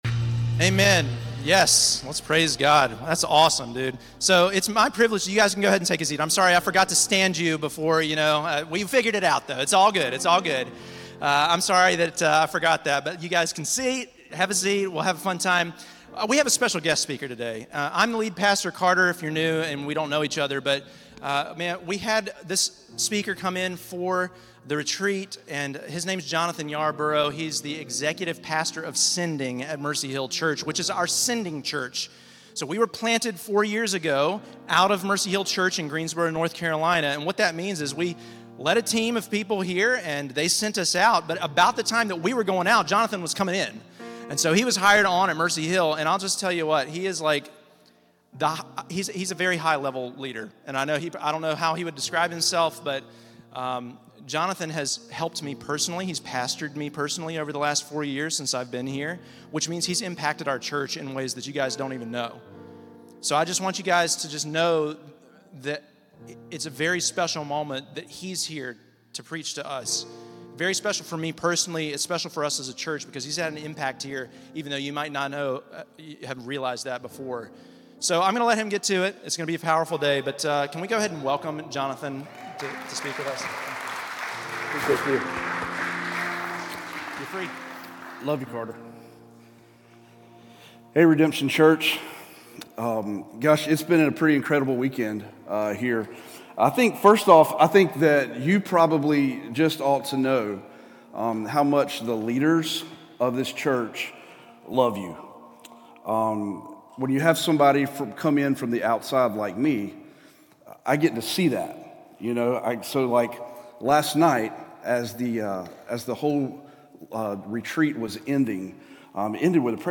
Series: Guest Speakers, Stand Alone Messages